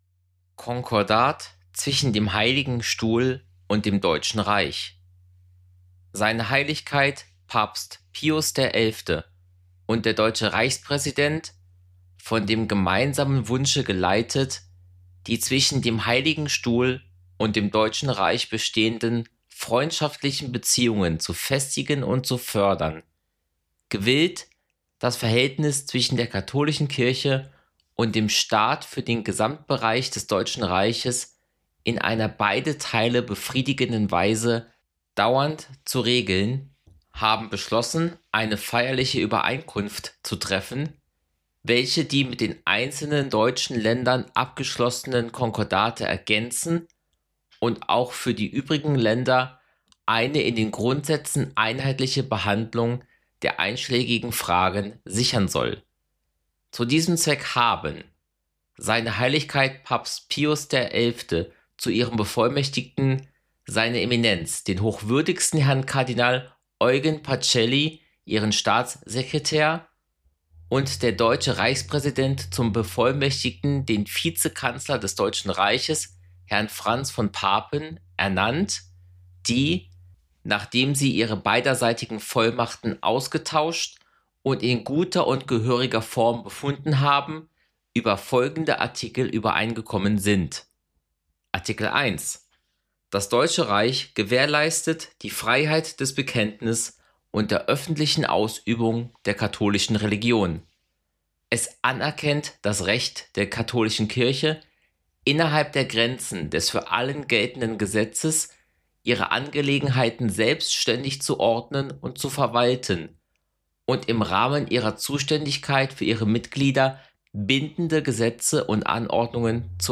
Ein Podcast über die Geschichte Europas. Gespräche mit Expert:innen, angefangen beim geologischen Entstehen der europäischen Halbinsel bis hin zum heutigen Tag.